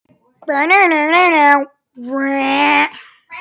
Haha Cat Sound Button - Free Download & Play